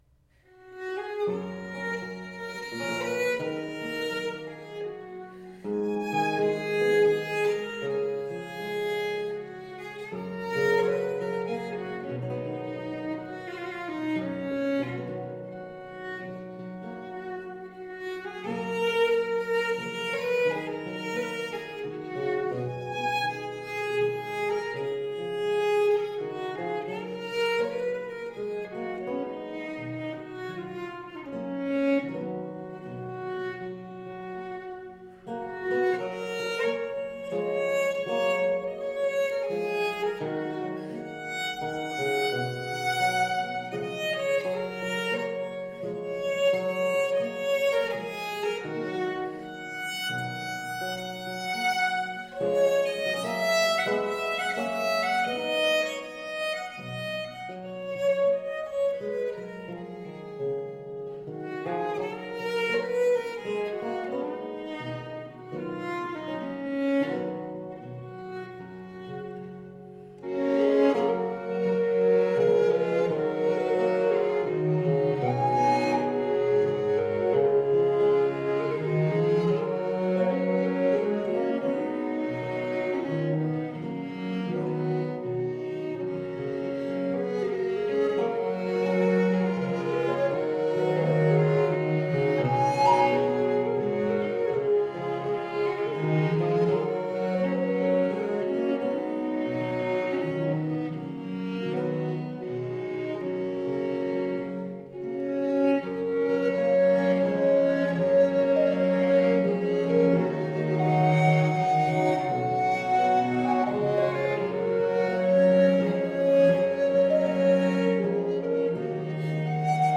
recorders and whistle
baroque violins
harpsichord